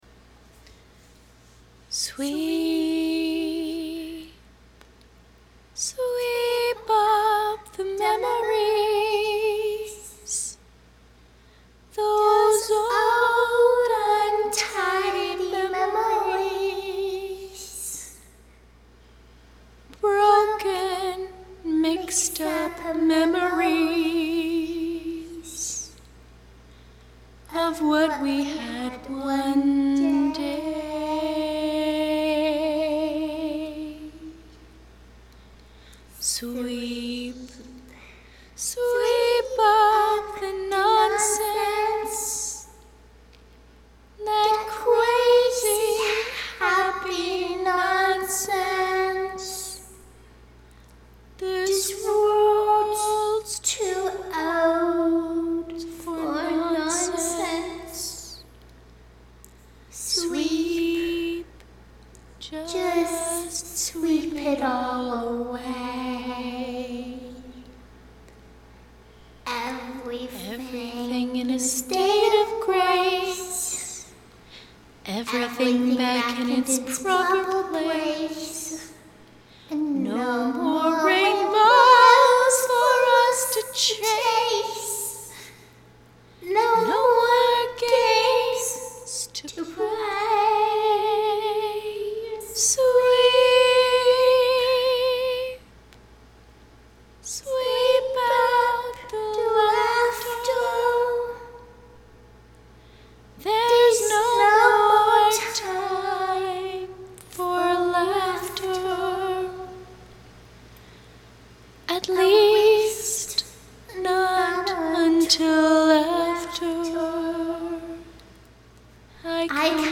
Posted in Other, Vocals Comments Off on